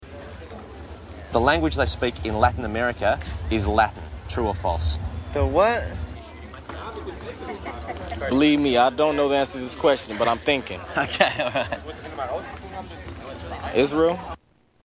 Tags: Media Stupid Americans Chasers Funny UK T.V. Show